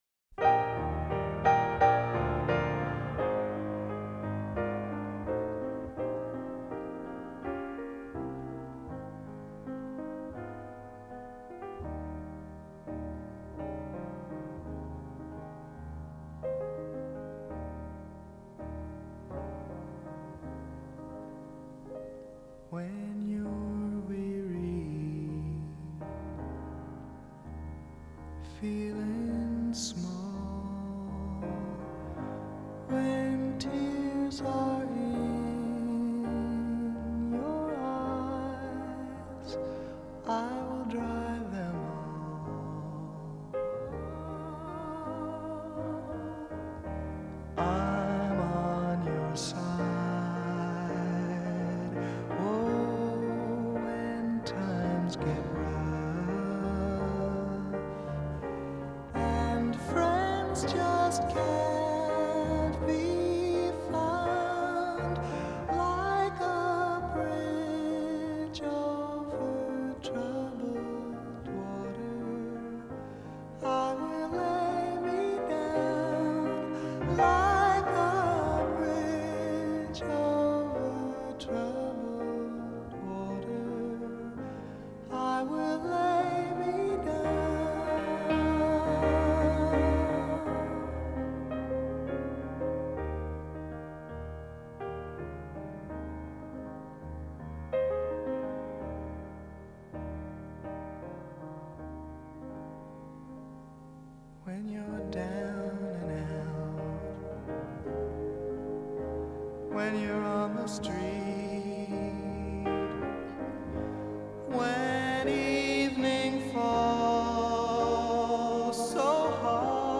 who plays piano on this record
Transferred from a 24/192 high-res download.